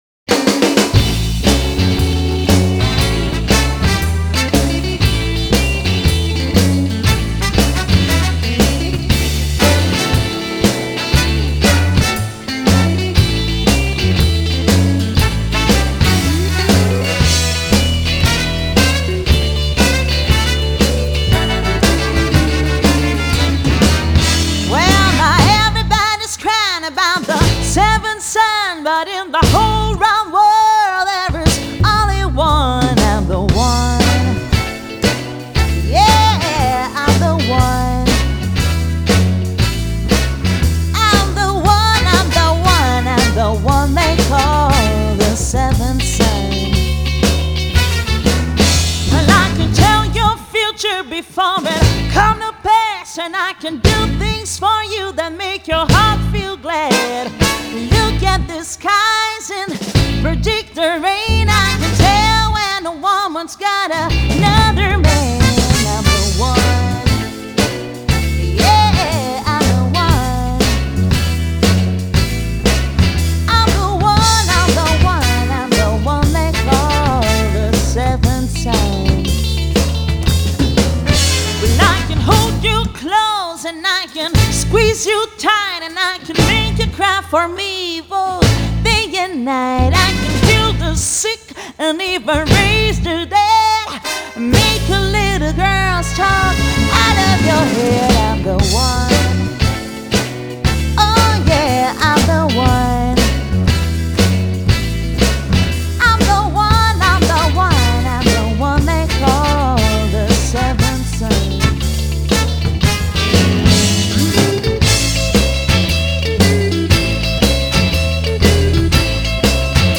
Genre: Blues, Vocals